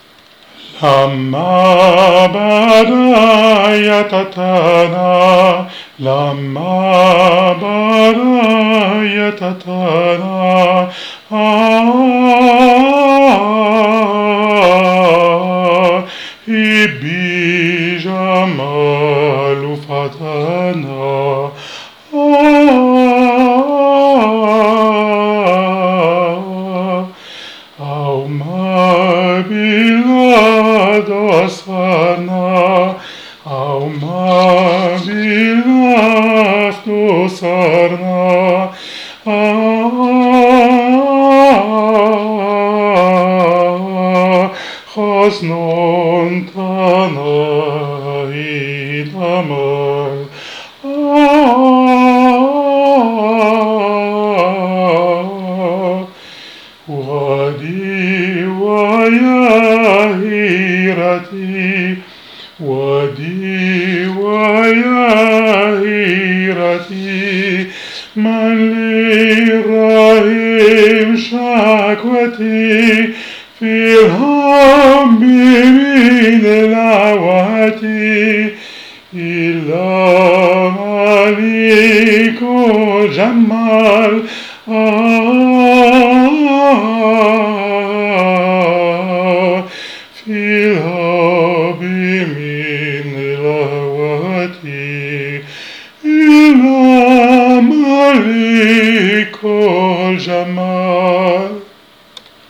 rythme samai thakil(anonyme) Harmonisation Yves Markowicz